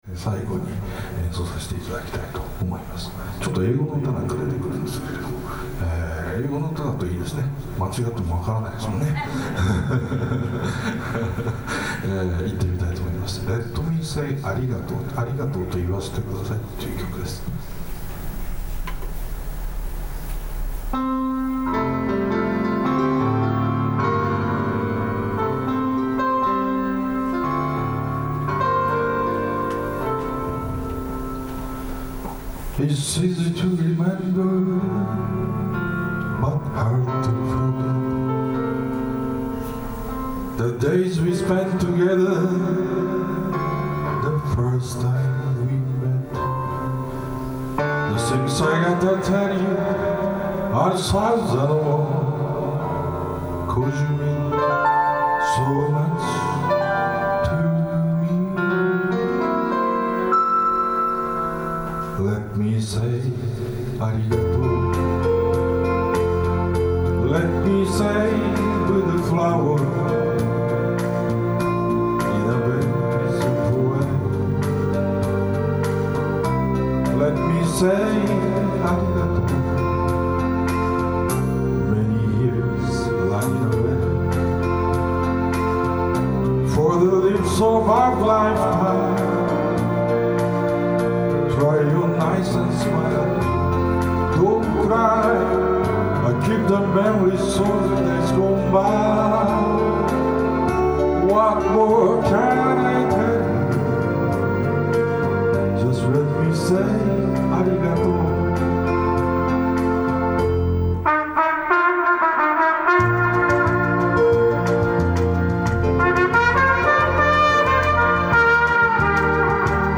今日は、我家から車で1時間ほどの福岡市内某所の老人クラブ連合会が催され、お声掛けをいただき、演奏に行ってきました。
僕のトランペット
という構成で、僕はたっぷり1時間のお時間をいただいて、60数名のお客様の前で演奏を楽しませていただきました。
僕の音（実況録音）
実況録音聴かせていただいて、会場のリラックスしたとても良い雰囲気が伝わってきましたよ♪